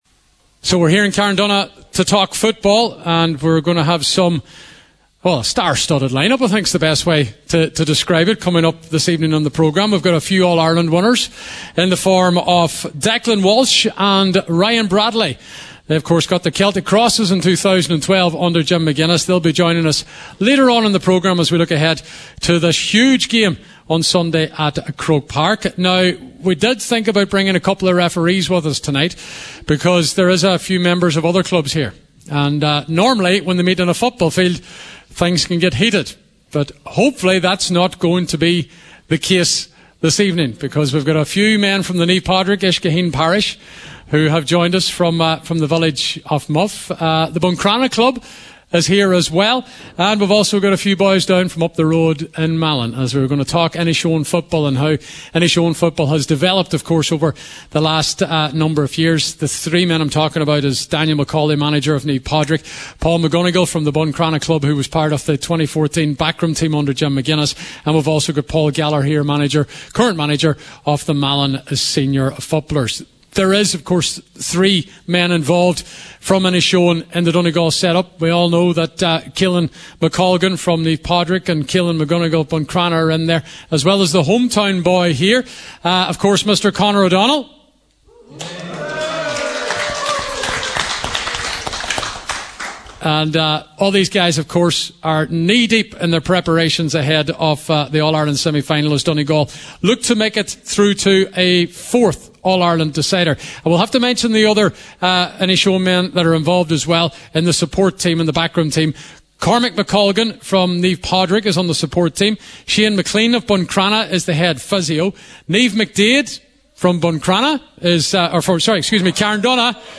The Score – LIVE from Carndonagh GAA Club